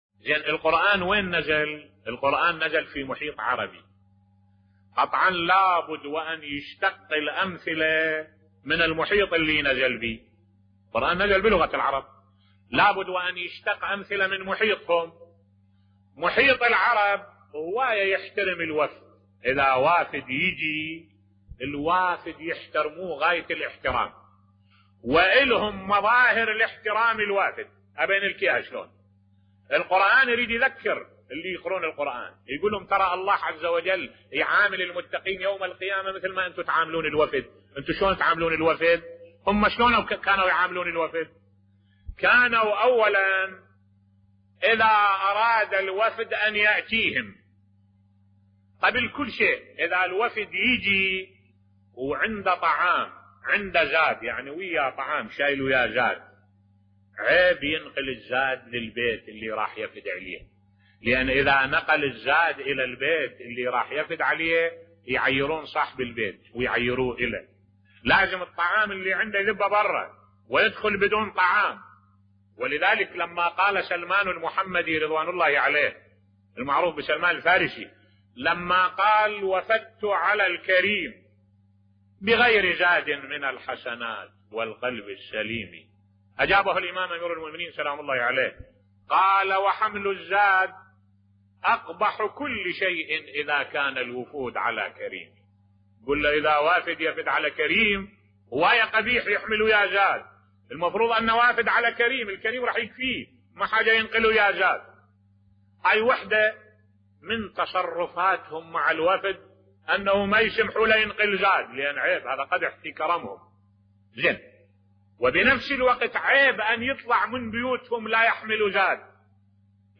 ملف صوتی شرح جميل لكيف كان يحترم العرب وفود الضيافة بصوت الشيخ الدكتور أحمد الوائلي